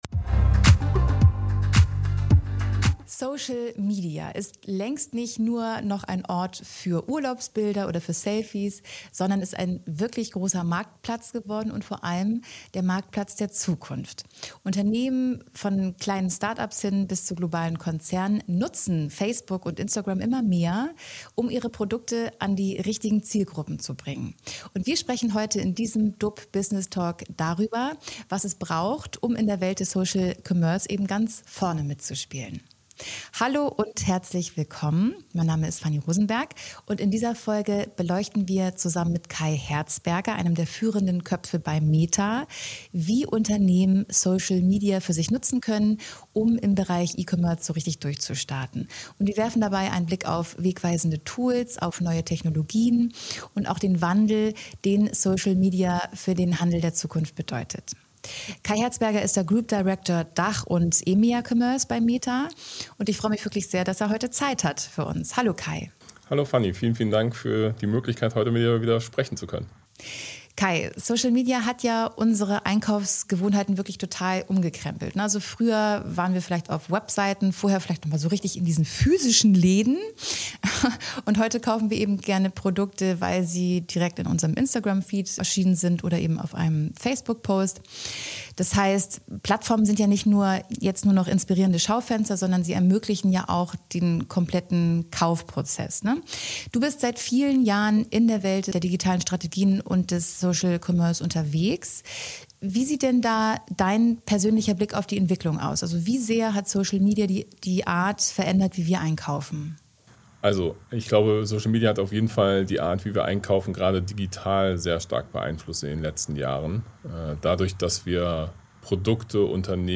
Business Talk